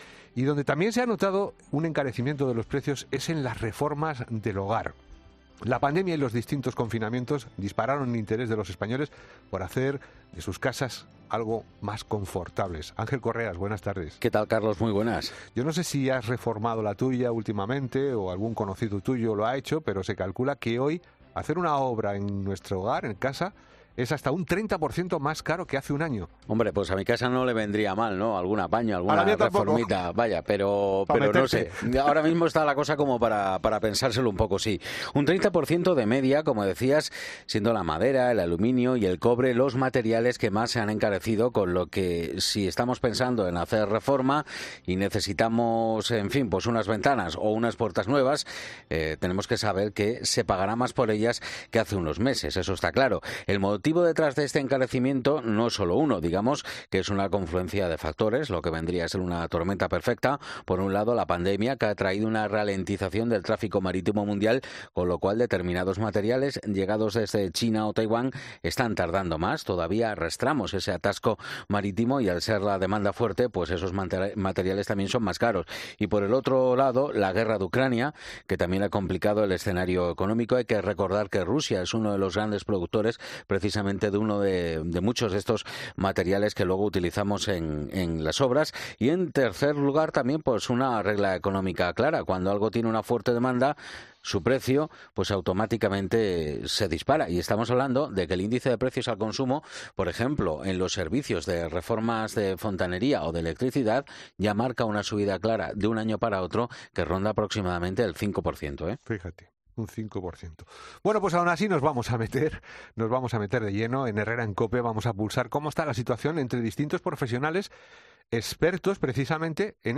Pintores, electricistas y ebanistas explican que algunos han tenido que bajarse el sueldo para no subir el precio de las reformas, donde la demanda...